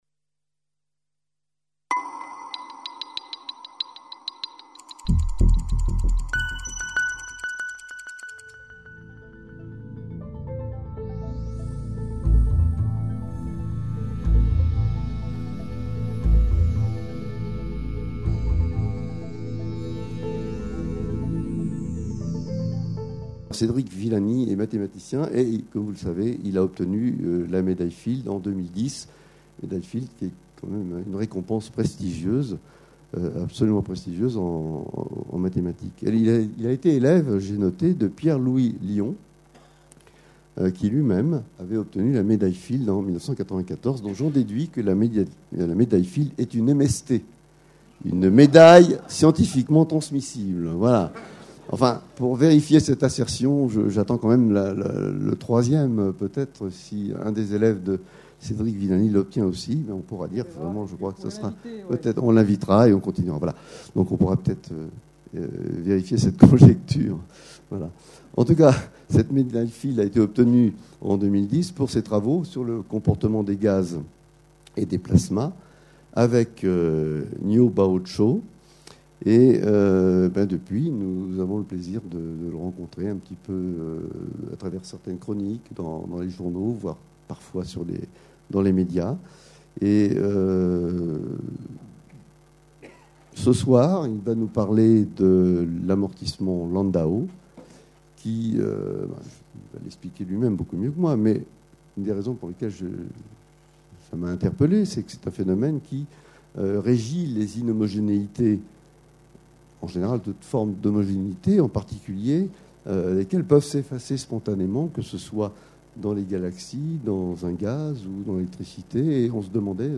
Conférence IAP du 2 mai 2012, par Cédric Villani, professeur de mathématiques de l'Université de Lyon et directeur de l'Institut Henri Poincaré - Médaille Fields 2010. Le devenir de notre système solaire, de notre galaxie, sont des questions qui agitent scientifiques et non scientifiques depuis des siècles.